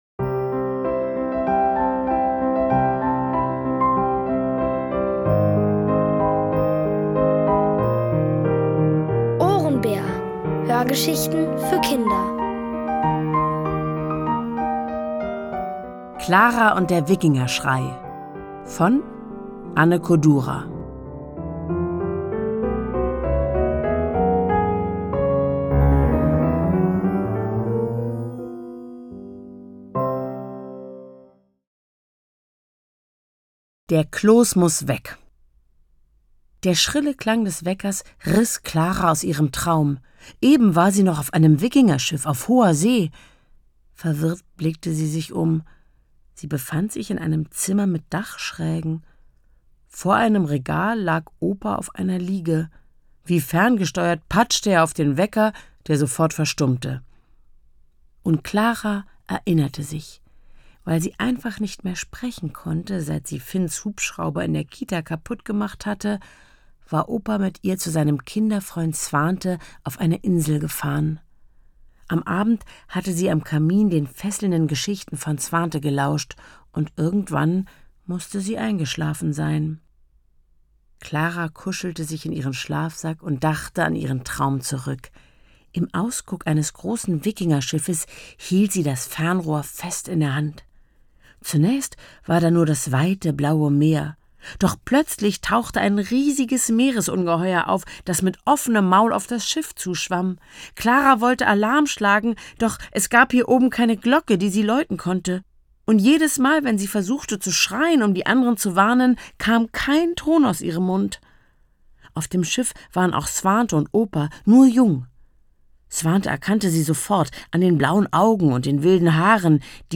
Von Autoren extra für die Reihe geschrieben und von bekannten Schauspielern gelesen.